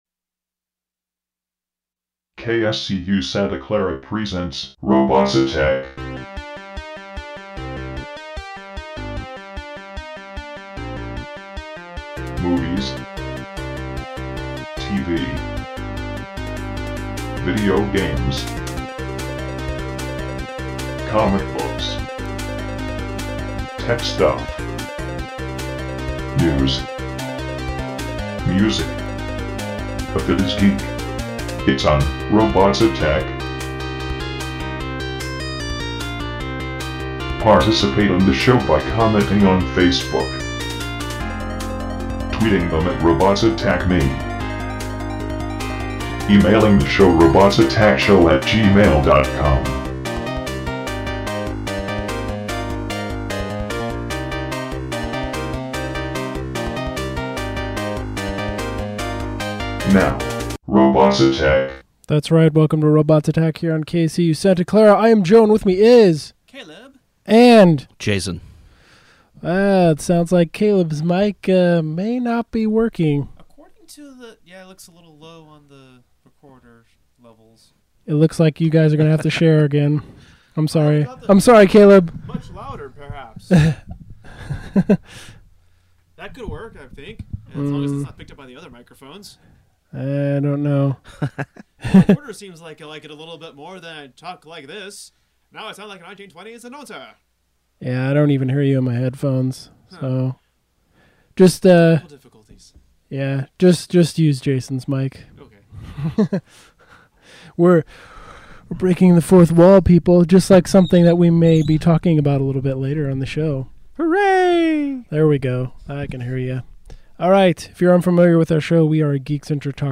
Geek Talk